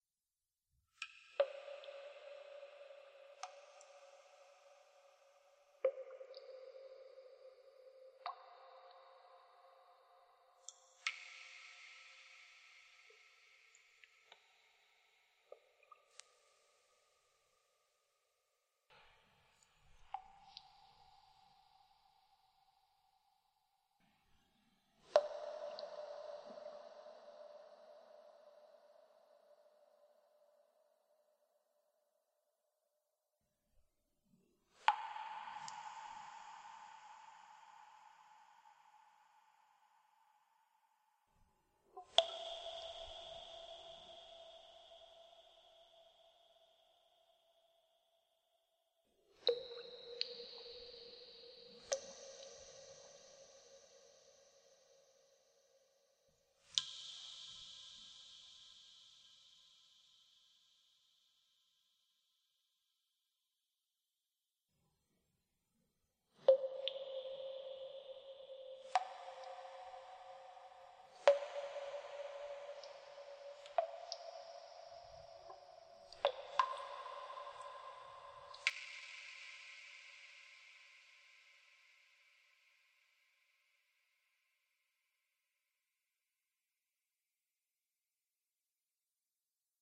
water dripping in cave